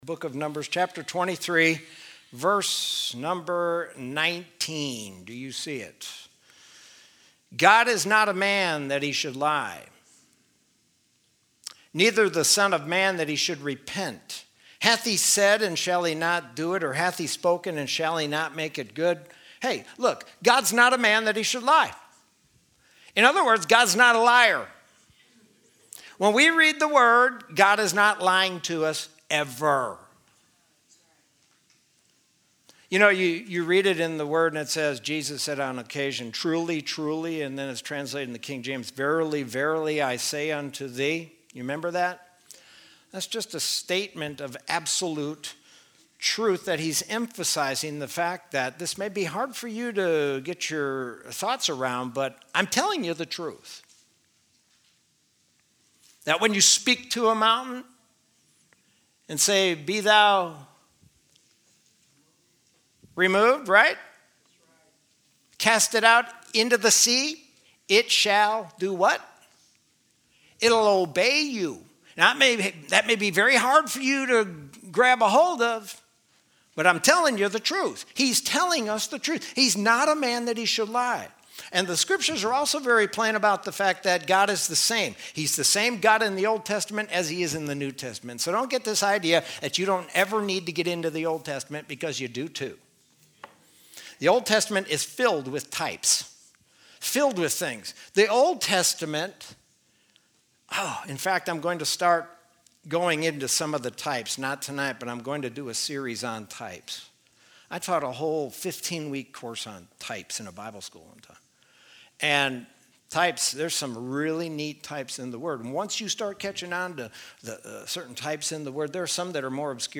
Sermon from Wednesday, May 27, 2020.